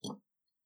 BottleFoley1.wav